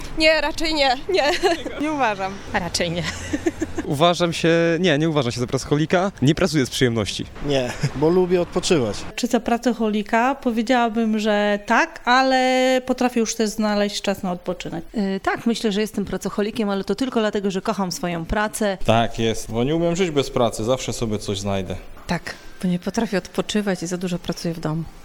Zapytaliśmy mieszkańców Stargardu czy uważają siebie za pracoholików.